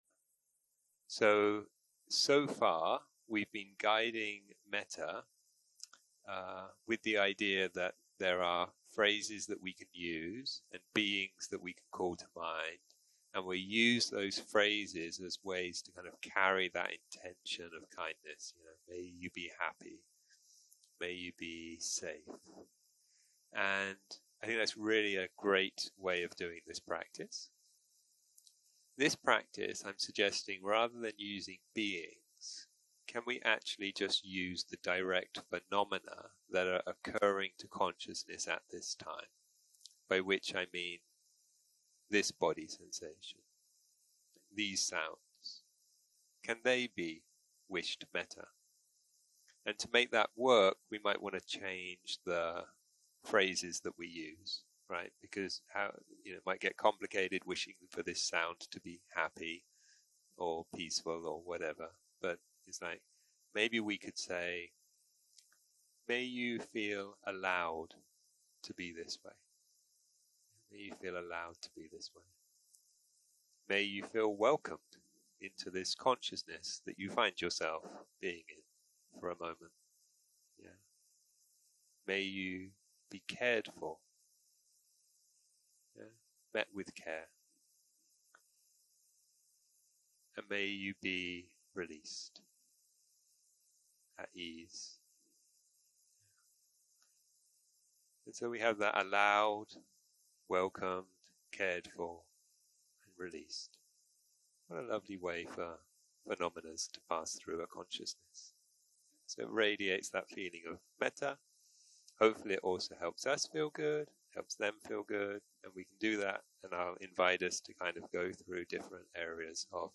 יום 6 - הקלטה 21 - צהרים - מדיטציה מונחית - מטא לתופעות
סוג ההקלטה: מדיטציה מונחית